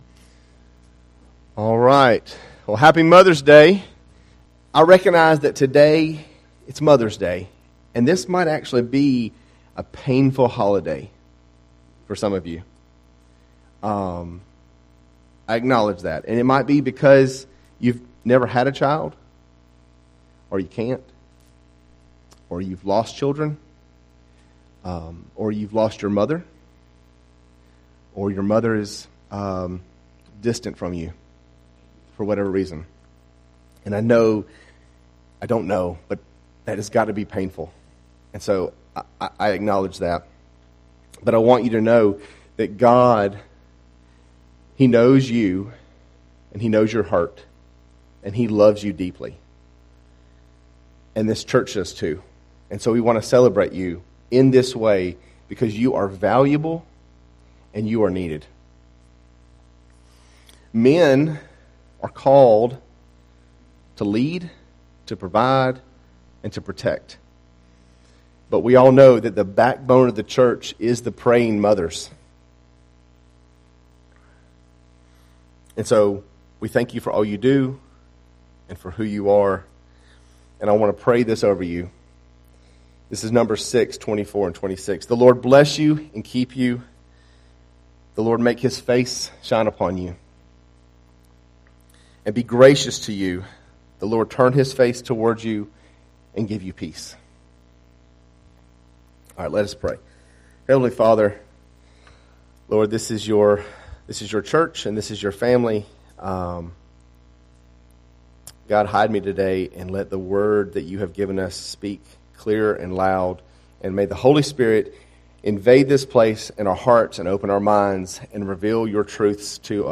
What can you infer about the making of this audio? A Baptist Church serving the South Ga area including Valdosta, Morven, and Quitman